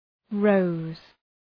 Shkrimi fonetik {rəʋz}